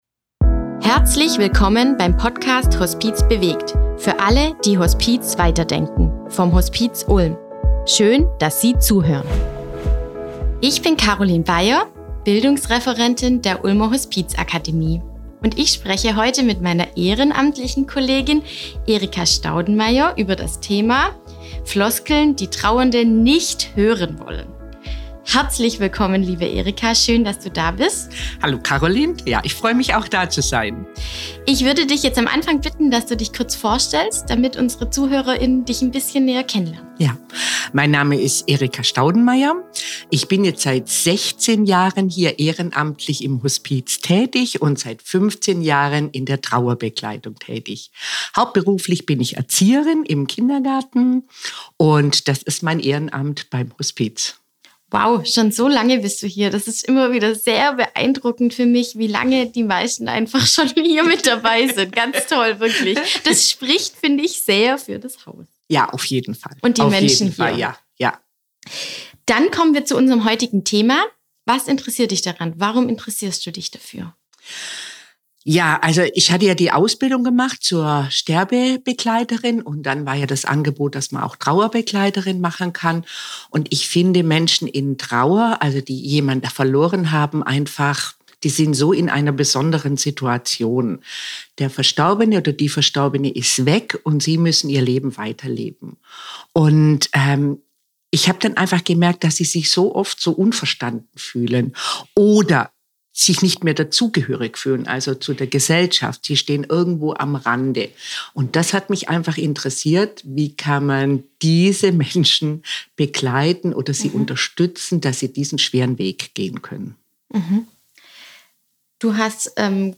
im Gespräch mit der ehrenamtlichen Mitarbeiterin